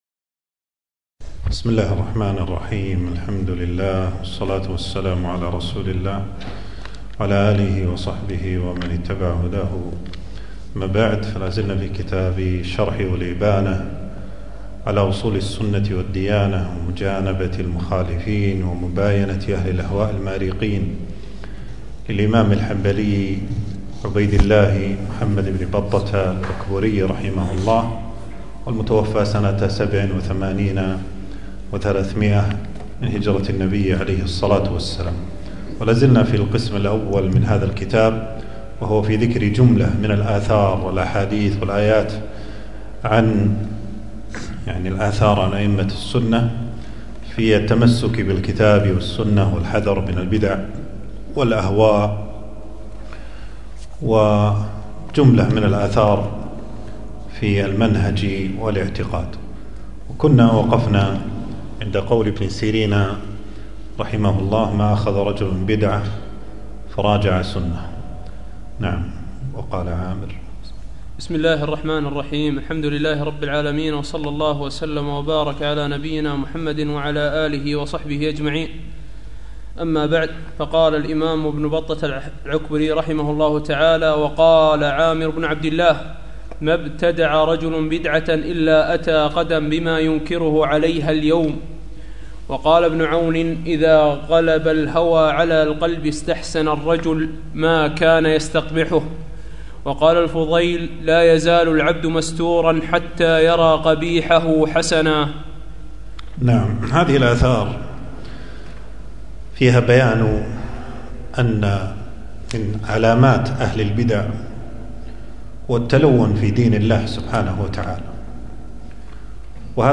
المكان: درس ألقاه يوم السبت 13 ربيع الثاني 1445هـ في مسجد السعيدي.